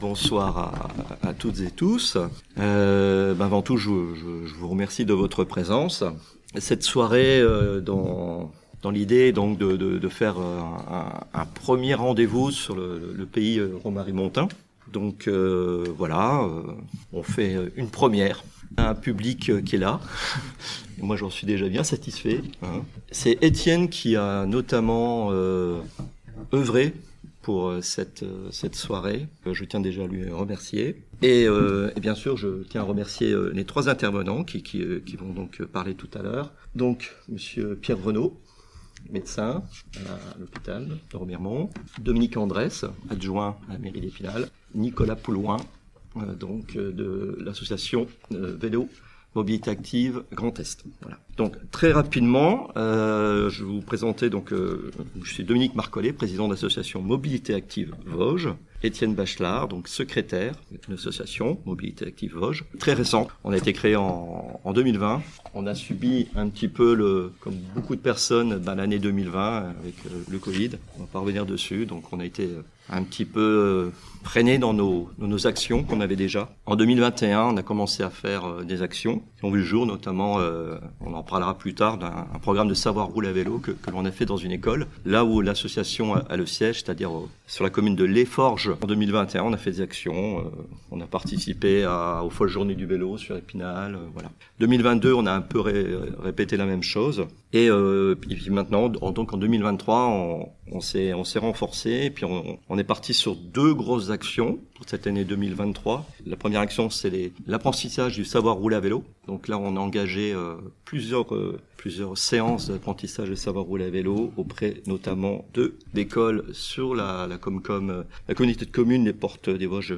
PODCAST - Table ronde : Le vélo quotidien, solution gagnante pour les petites villes de demain ?